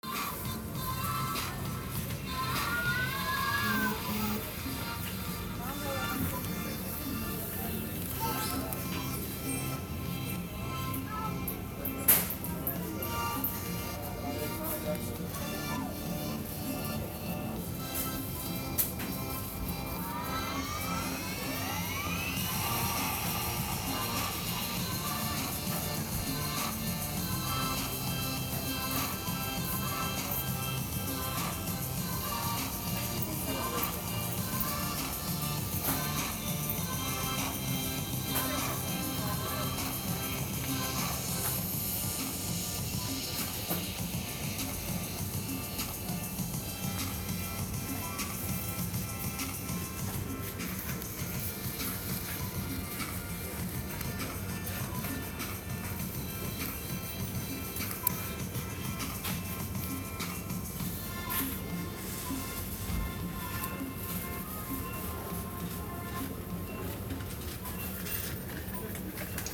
Před časem, nechávali hrát v supermarketech Albert i mj. tuto instrumentální hudbu.